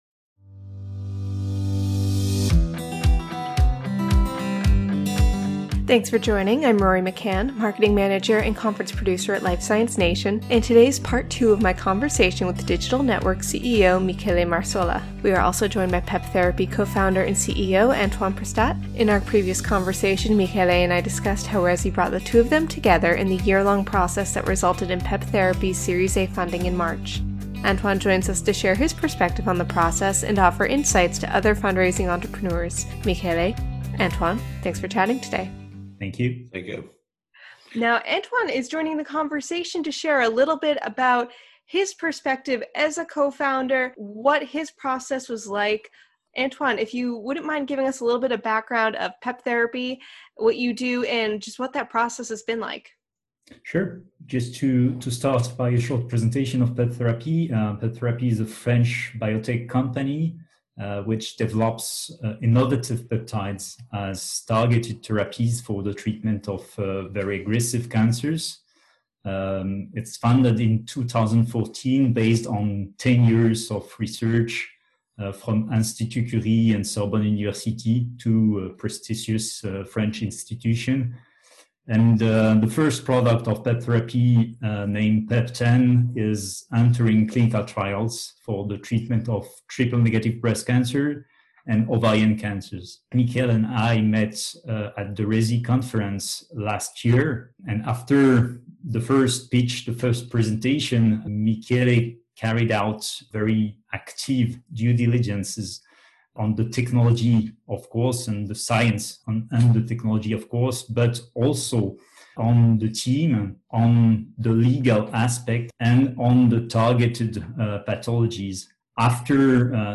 In Part II of our two-part interview